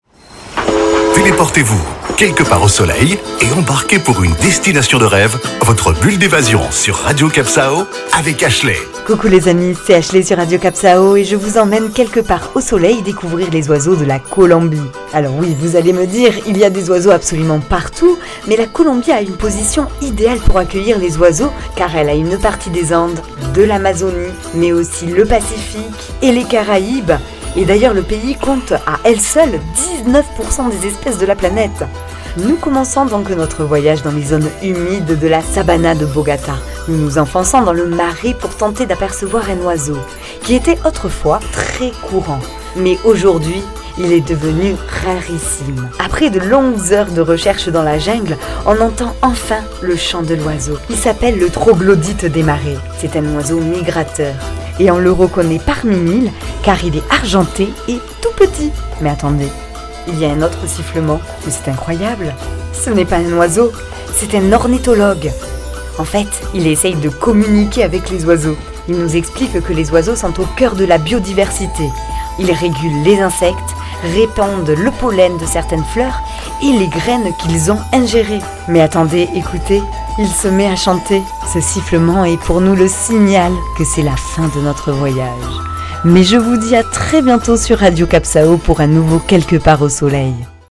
Carte postale sonore : devenez ornithologue en observant les magnifiques couleurs des oiseaux tropicaux et en imagineant leurs chants dans vos oreilles.